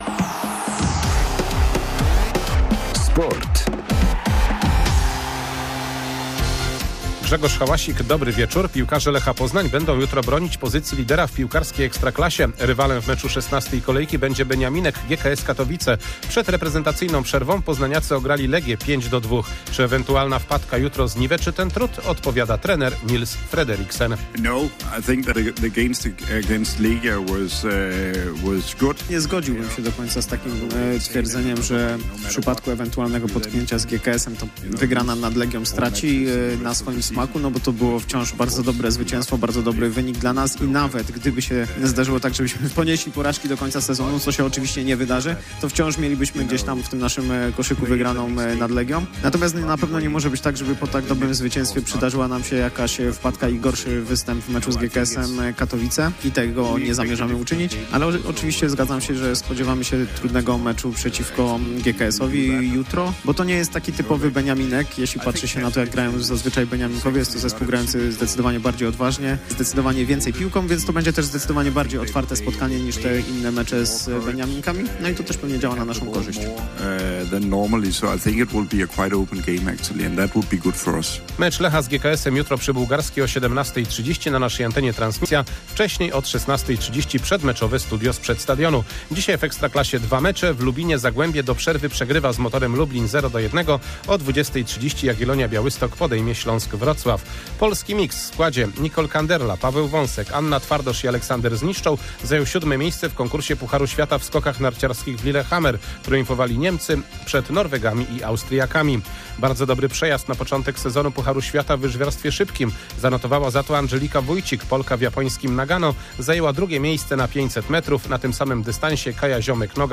22.11.2024 SERWIS SPORTOWY GODZ. 19:05